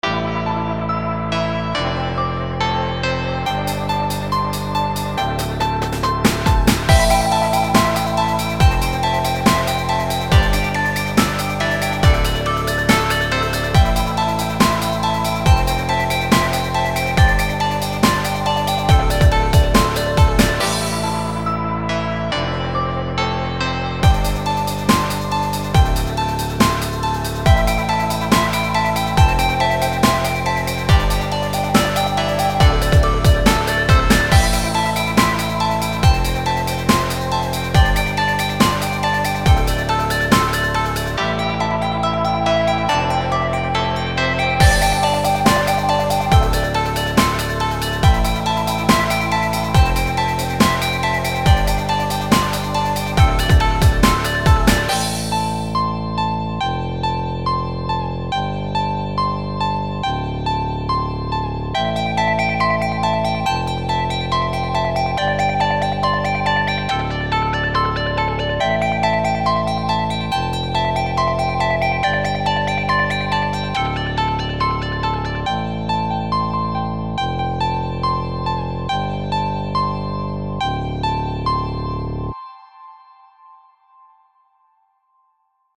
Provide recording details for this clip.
I composed everything myself in the piano roll, there are no arpeggio generators in here =P